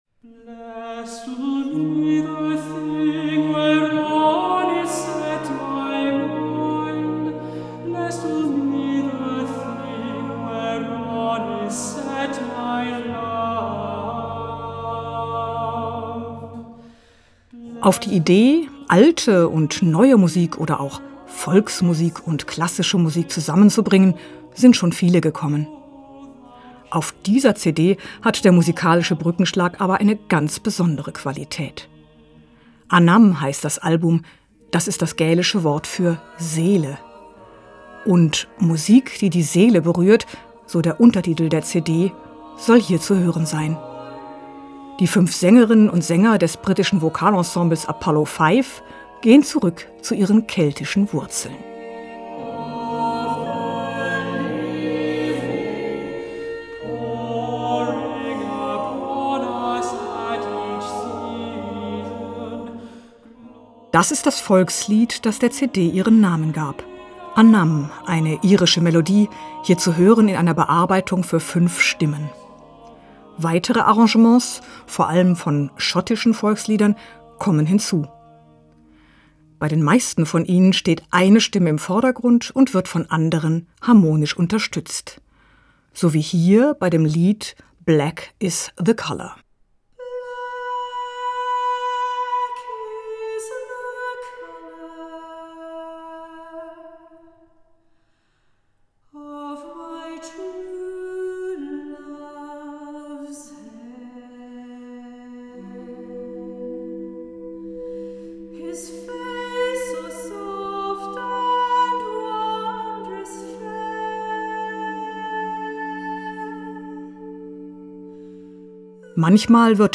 Radio-Podcasts in voller Länge kostenlos live hören: Seelenmusik: Das britische Vokalquintett Apollo5 verbindet keltische Musik mit Avantgarde -